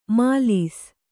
♪ mālis